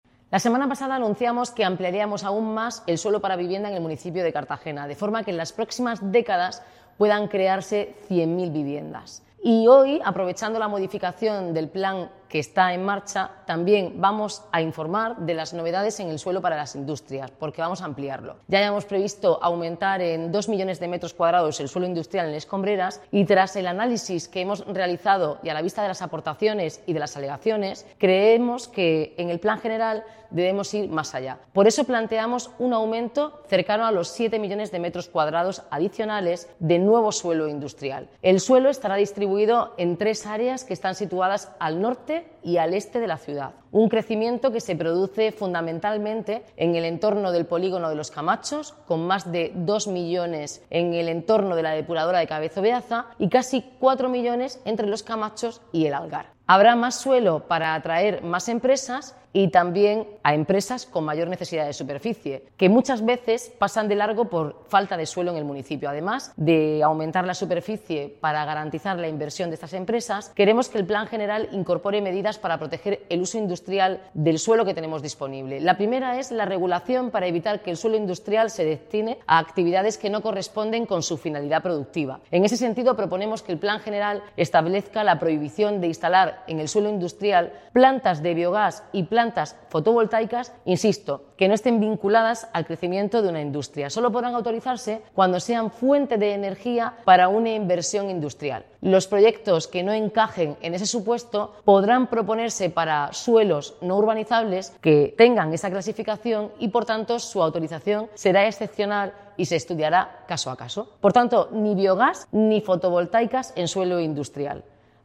La alcaldesa Noelia Arroyo explica que esta propuesta se incorpora a la aprobación provisional de la modificación del Plan General de Ordenación Urbana